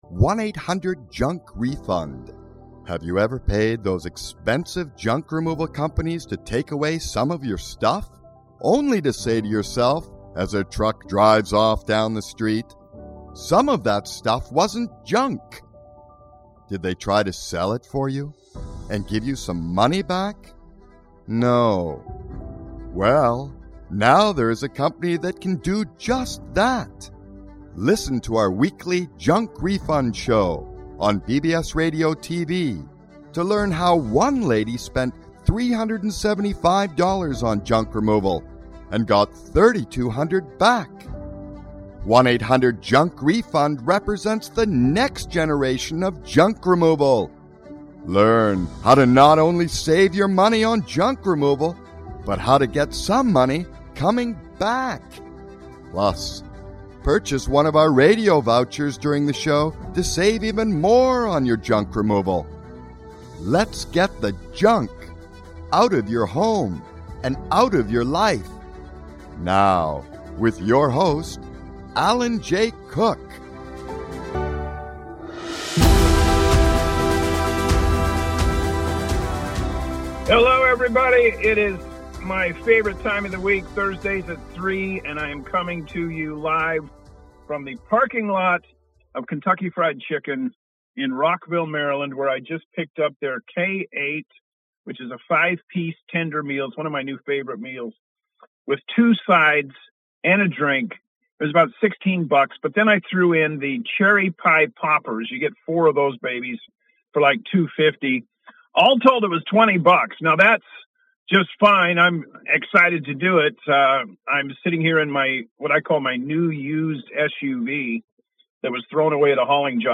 Live from the parking lot of Kentucky Fried Chicken in Rockville, Maryland